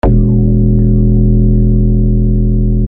Trance UFO Bass JD 800 E1 ufo_bass
ufo_bass.mp3